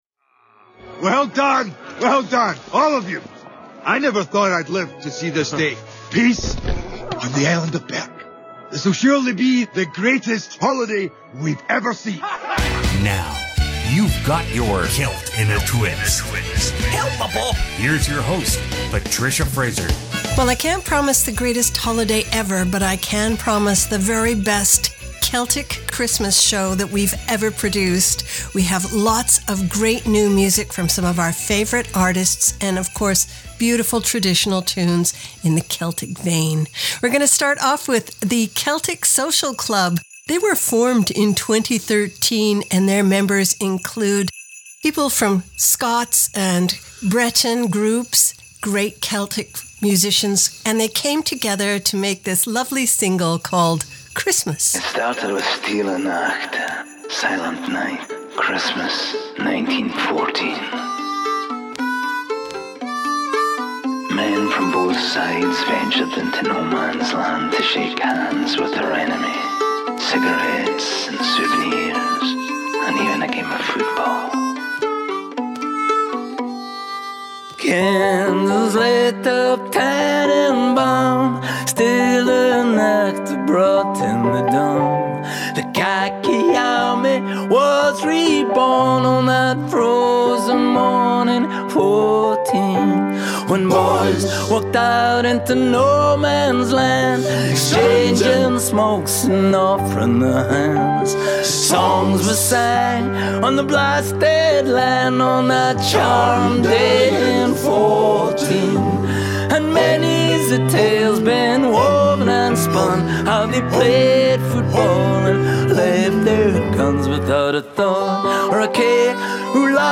Celt In A Twist Contemporary Celtic Christmas Special 2016!